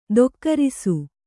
♪ dokkarisu